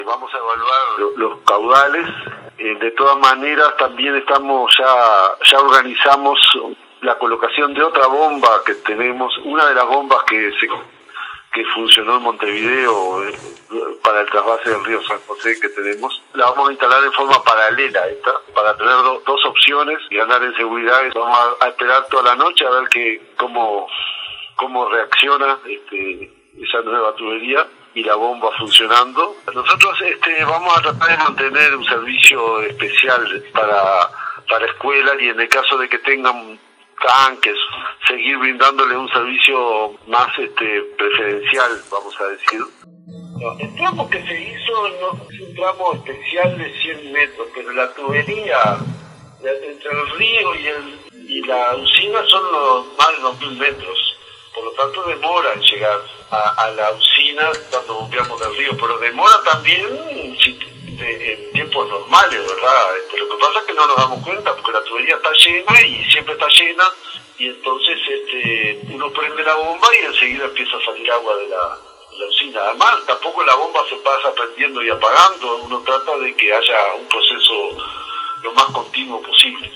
"Ya organizamos la colocación de otra bomba, una de las que funcionó en Montevideo. La vamos a instalar en forma paralela a esta para tener dos opciones", informó el presidente de OSE, Raúl Montero.
corresponsal en Durazno.